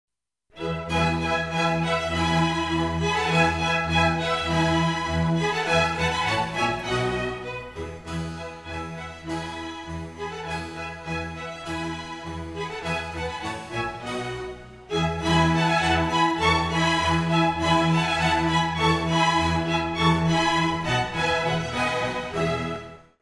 • Качество: 128, Stereo
без слов
инструментальные
оркестр
торжественные
классическая музыка